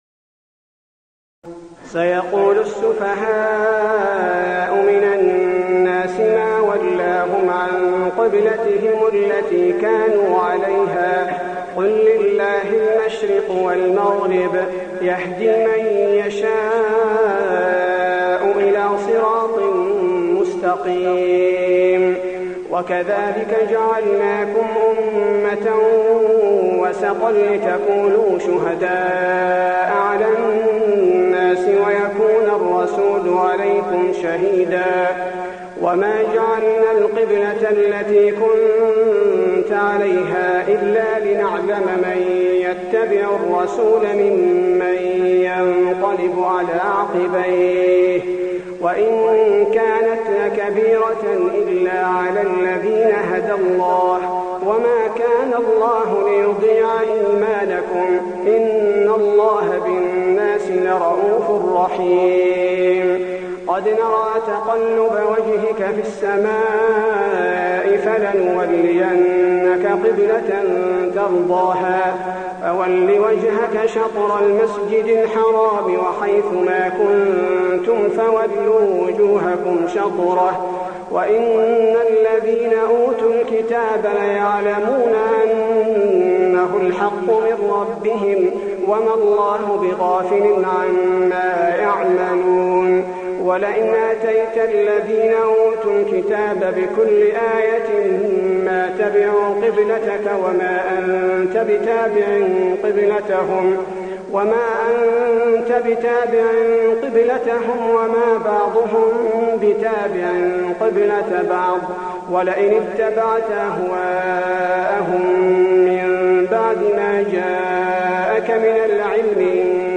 تراويح الليلة الثانية رمضان 1423هـ من سورة البقرة (142-188) Taraweeh 2 st night Ramadan 1423H from Surah Al-Baqara > تراويح الحرم النبوي عام 1423 🕌 > التراويح - تلاوات الحرمين